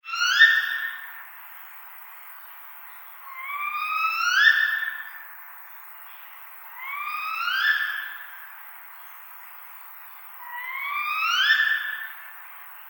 Screech-owl-sound.mp3